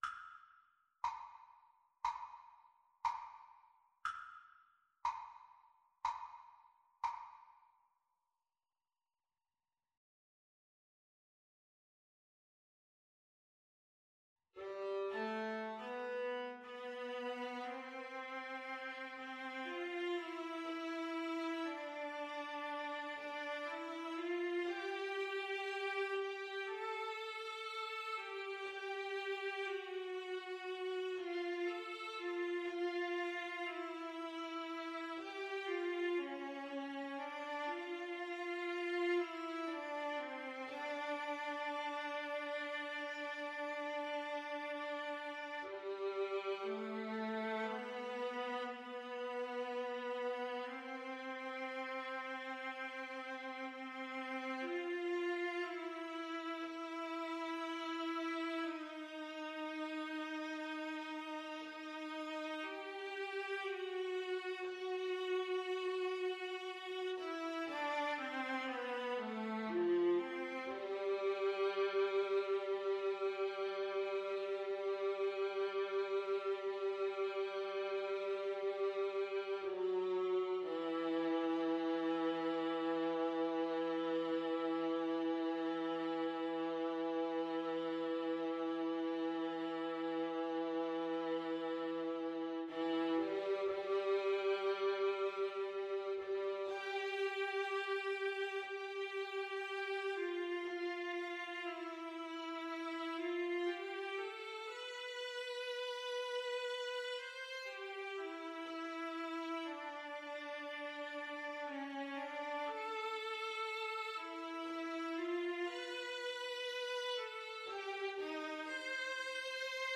Sehr langsam